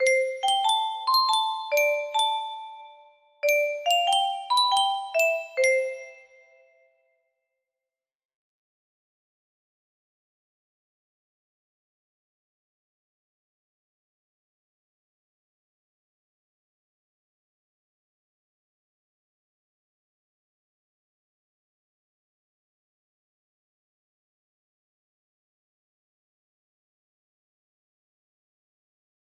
Wow! It seems like this melody can be played offline on a 15 note paper strip music box!